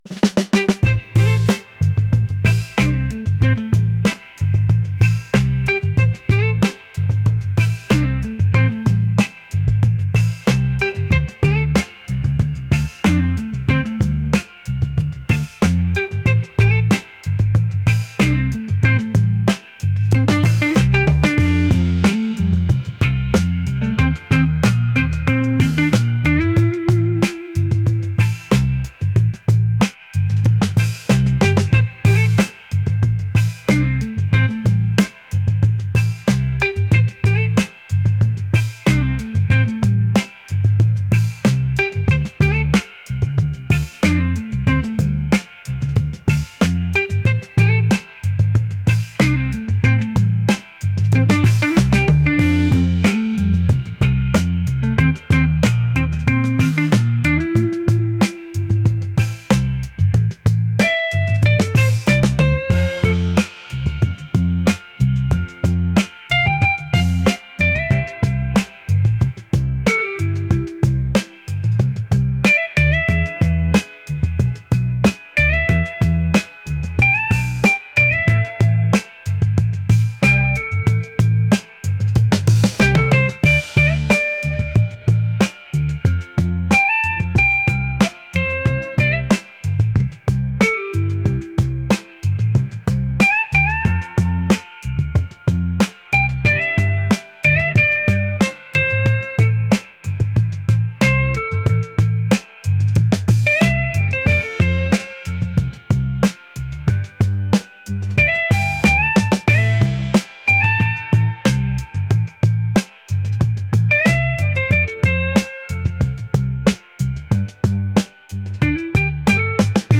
soul | funk | groovy